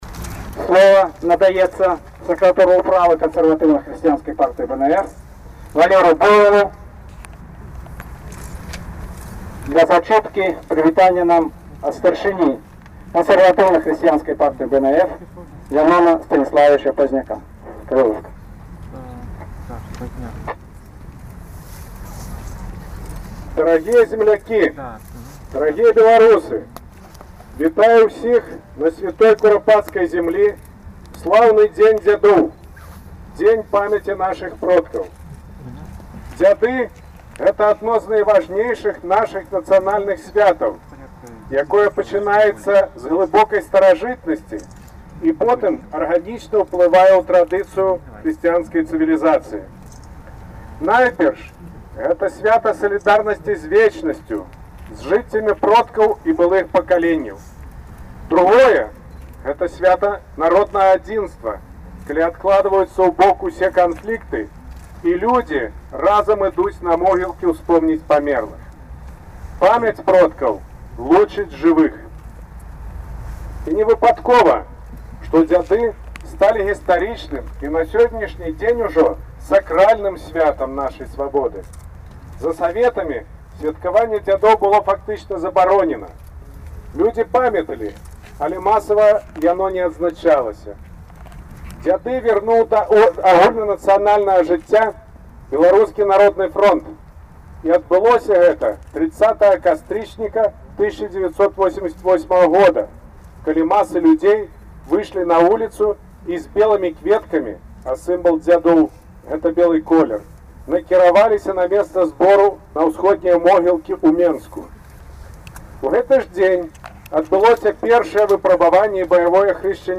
зачытвае зварот